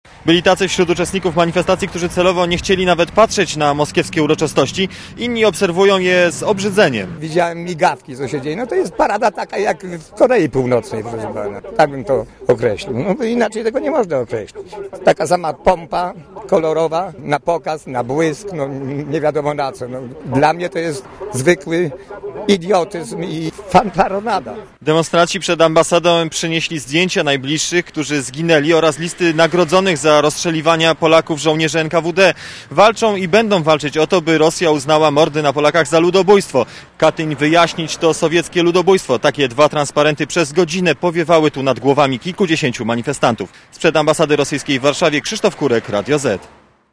Relacja
manifestacja-ambasada.mp3